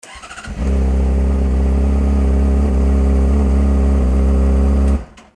純正マフラーのアイドリング音
ほぼ純正マフラーと同じ音圧になりました。